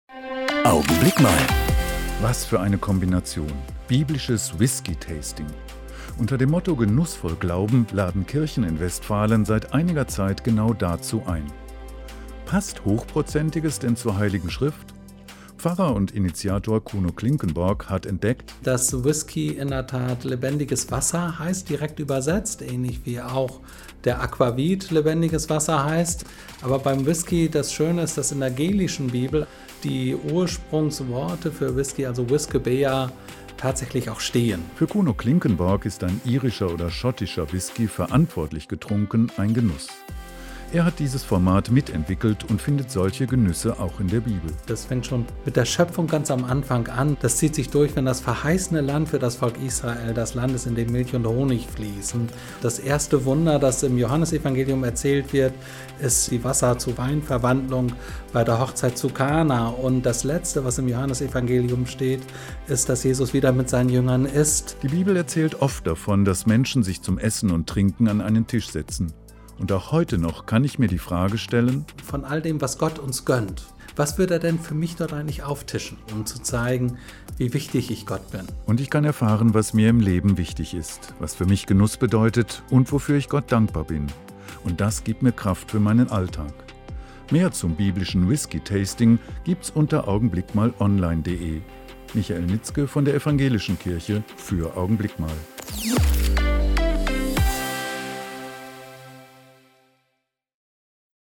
Radioandachten